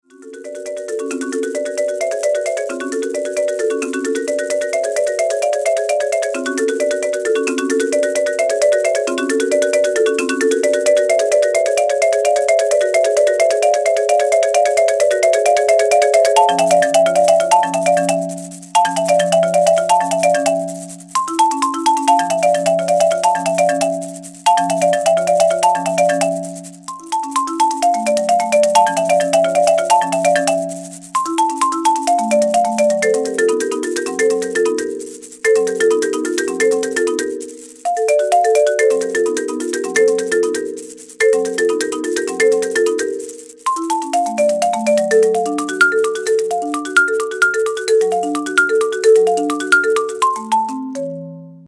Solo Marimba